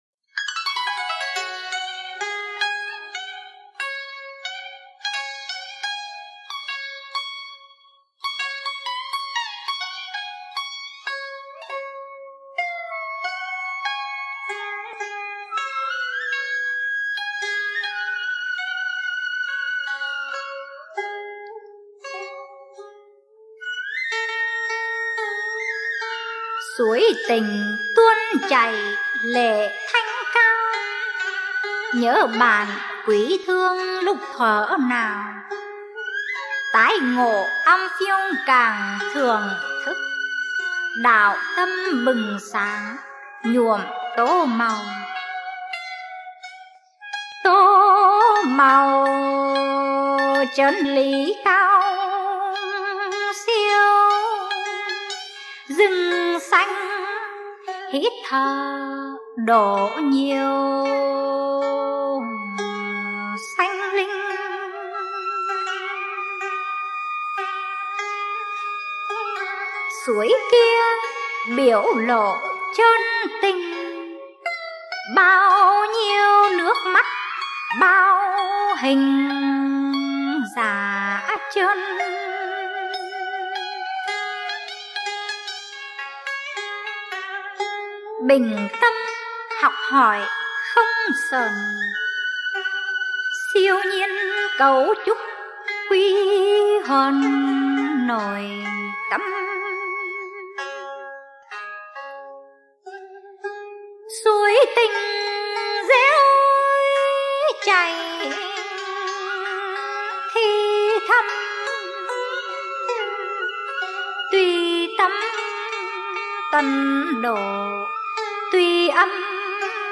Dân Ca & Cải Lương
Ngâm Thơ